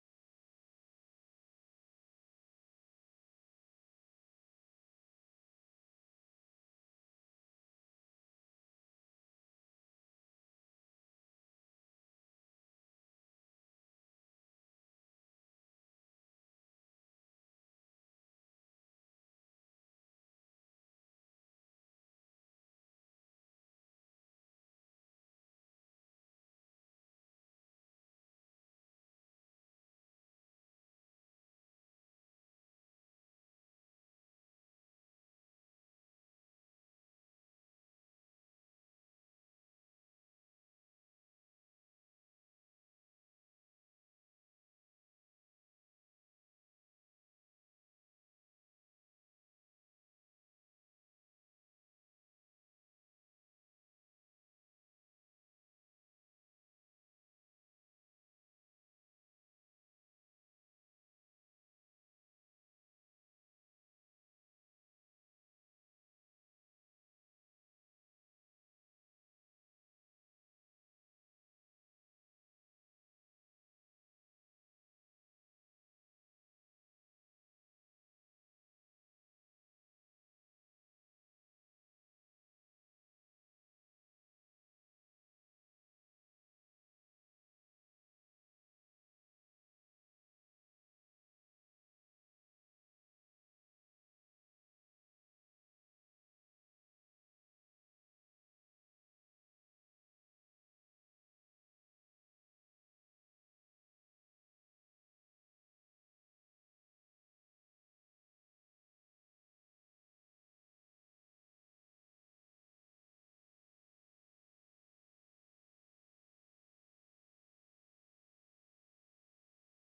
Functie: Presentator
Opgewekte muziek speelt.